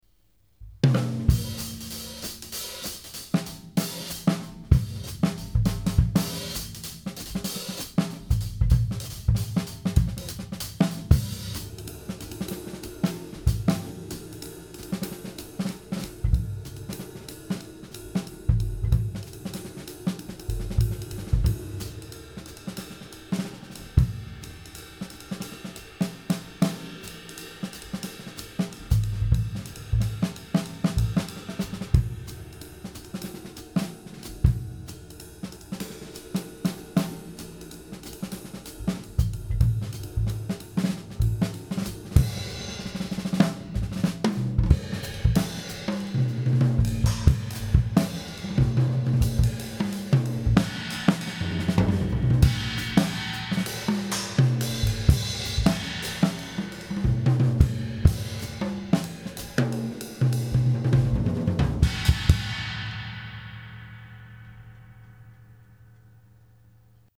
Love the combo, flat and ride, and that the ride sounds just like a more open version of the flat. As a group they sound fantastic The hats sound as if they may be a little harder than the others, but it may just be the way they're being hit as you're warming up.
Kick tuning has not changed.
Very Nice ;) , the China sounds real pangy, does it have a sorta rigid feel, with a shorter lip.
The hihats have attitude - I'm sure they'll be fantastic as they settle a bit...
The ride is unusual - but very sweet.